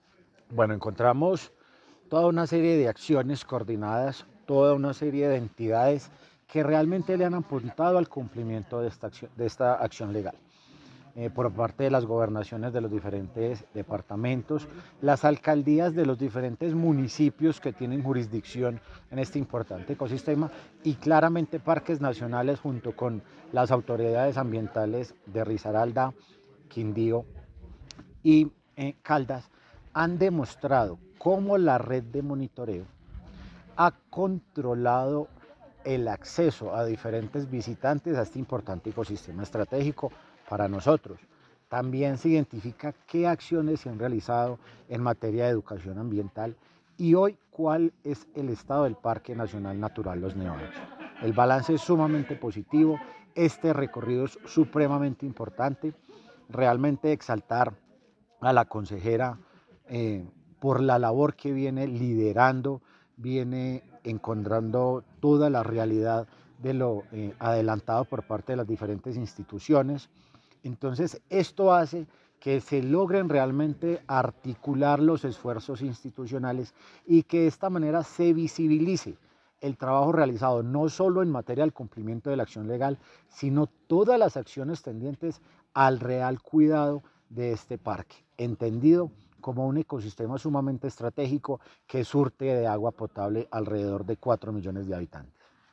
AUDIO-DIRECTOR-RECORRIDO-PARQUE-LOS-NEVADOS-1-1.mp3